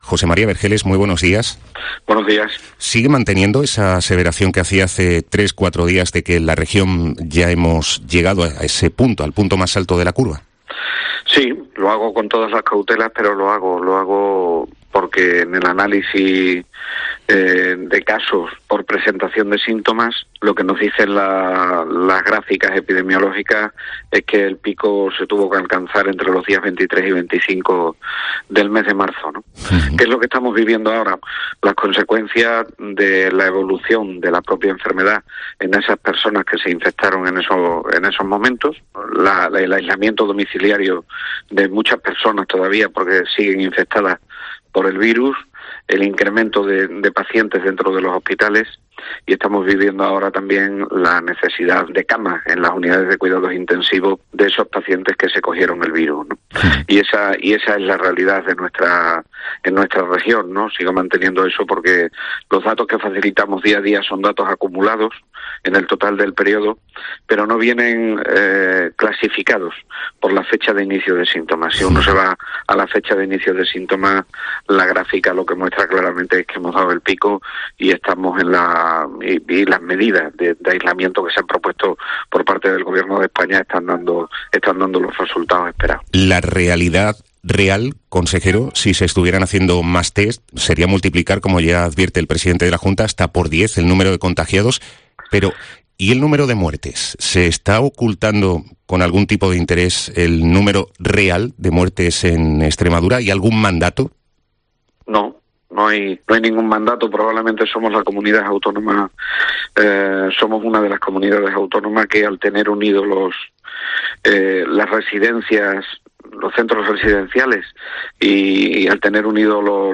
AUDIO: El Consejero de Sanidad extremeño lo ha reiterado, este viernes, en una entrevista en COPE